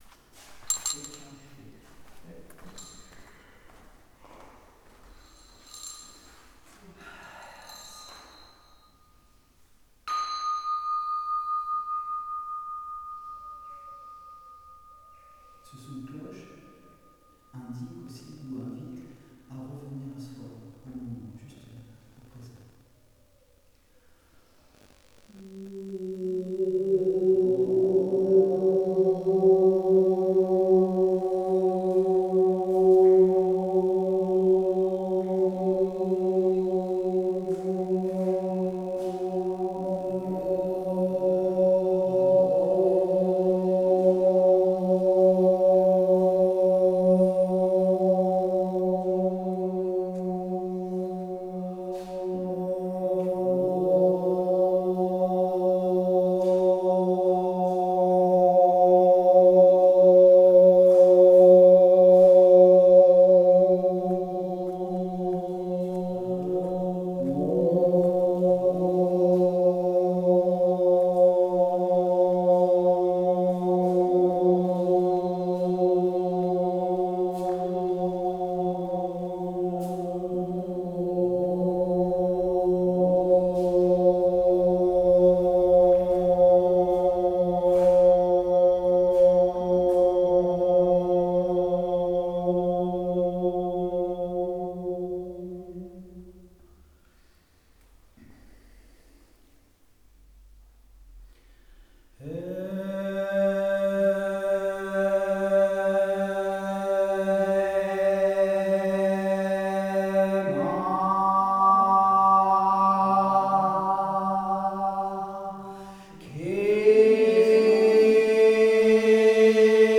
Last repete représentation - Nectar-vibratoire